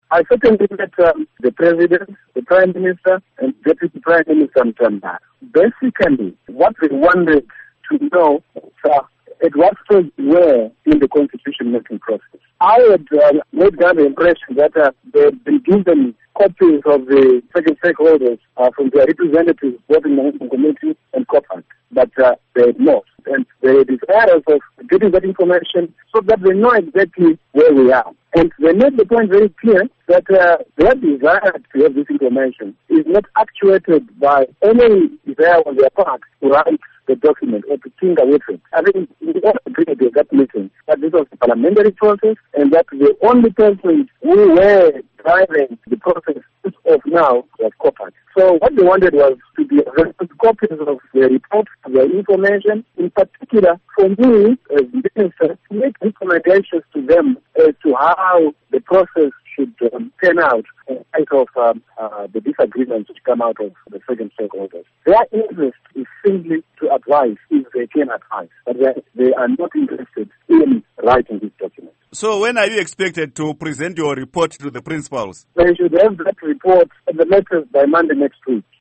Interview With Minister Eric Matinenga